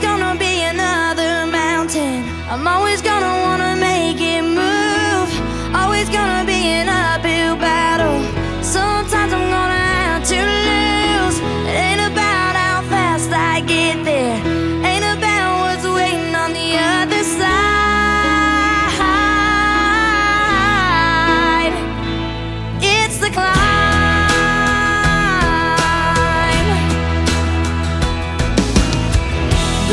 • Soundtrack
The instrumentation includes piano, guitar, and violins.